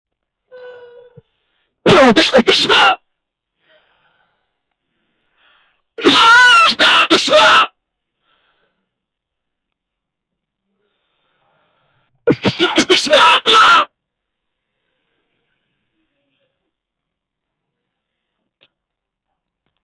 old rebal man, coughing and shits himself and then screams "MARTY!" "HELP ME!" "I SHITTED" 0:20 Created Nov 8, 2024 2:10 PM A sudden record scratch that interrupts music. 0:11 Created Sep 5, 2024 11:06 PM
old-rebal-man-coughing-an-rb2gpzg7.wav